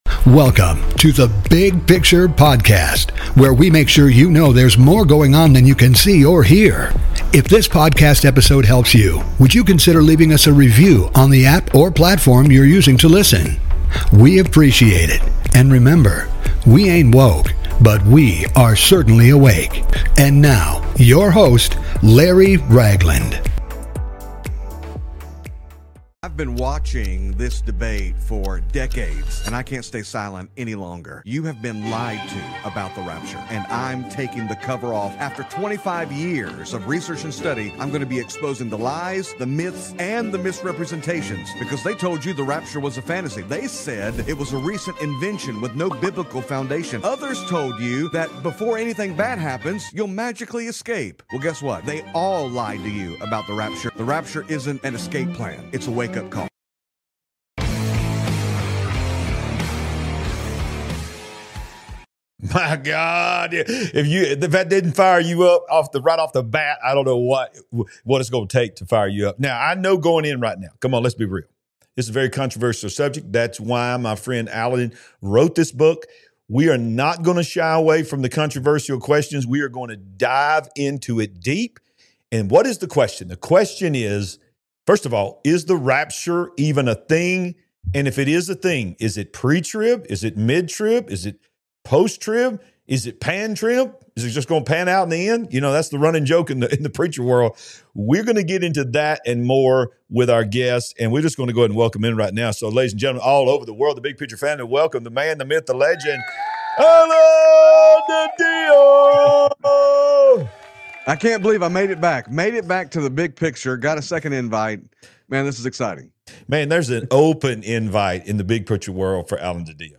Don’t miss this eye-opening teaching that could change your eternity.